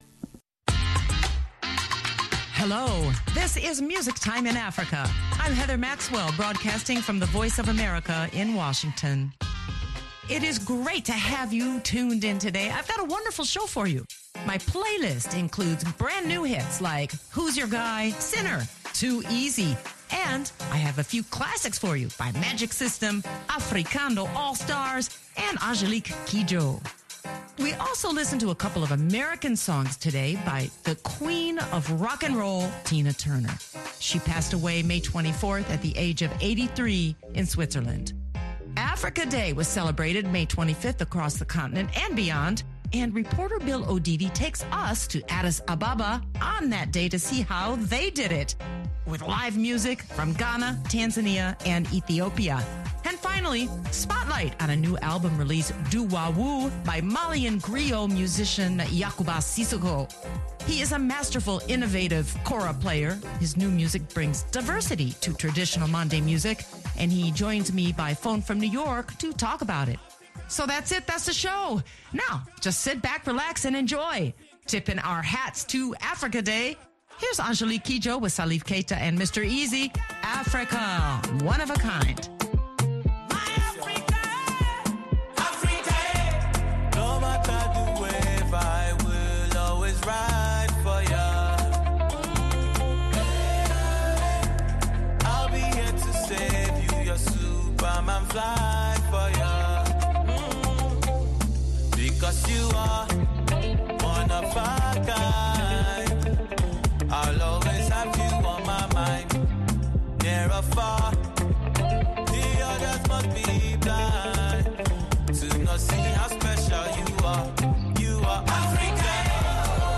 The Africa Union celebrates its 60th anniversary May 25, marked as Africa Day. In Addis Ababa, a major concert supports pan-African dialogue and unity with performances by highlife band Santrofi (Ghana) and Amhara traditional music group Gonder Fasiledes (Ethiopia).